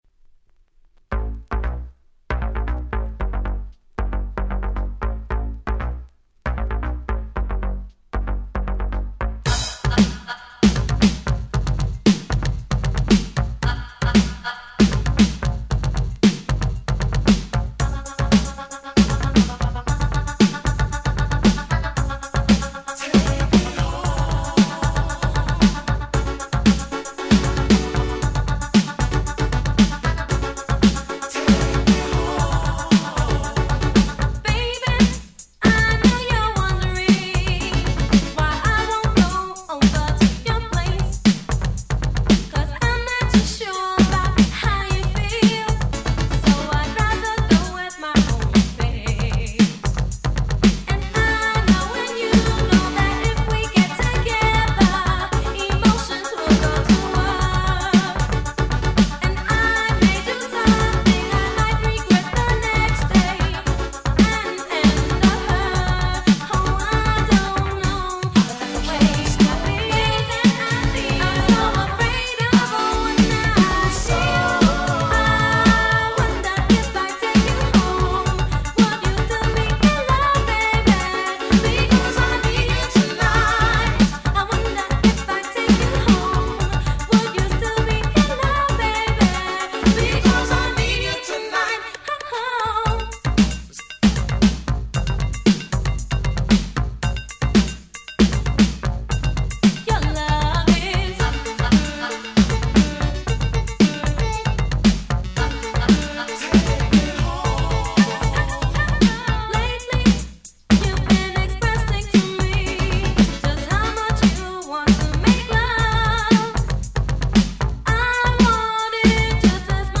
84' Super Hit Disco !!
80's ディスコ Disco